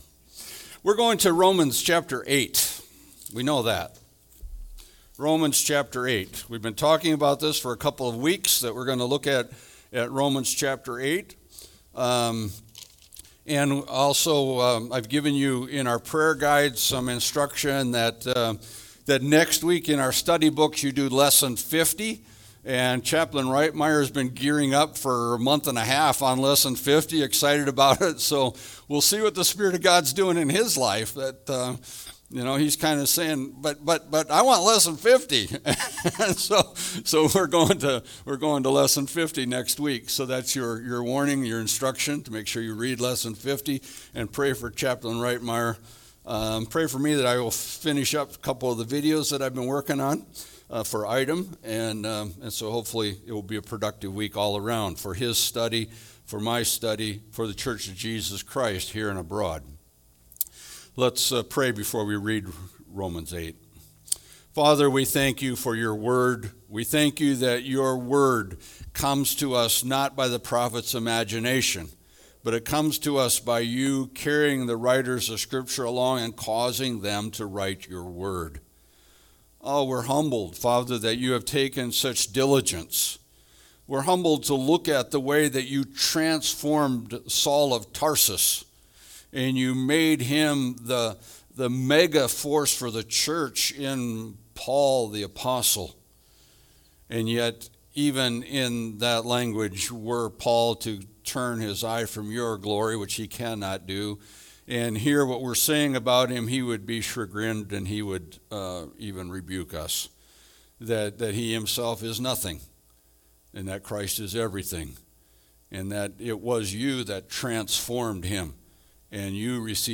Passage: Romans 8 Service Type: Sunday Service